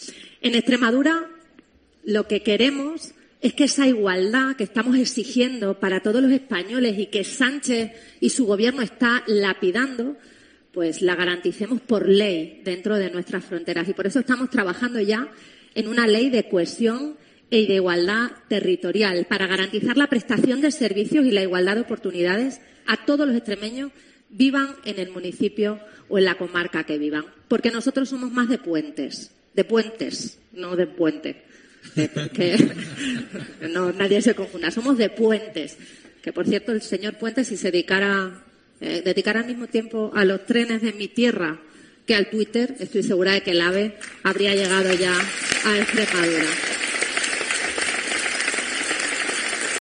Así se ha pronunciado la presidenta del PP en Extremadura durante su intervención en el acto 'Construir España desde las Comunidades Autónomas', cita organizada por los 'populares' gallegos en el Auditorio de Palexco de A Coruña.